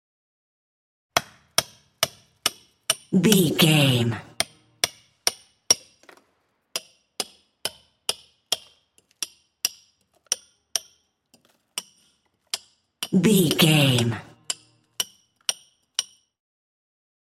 Chisel
Sound Effects
mechanical
foley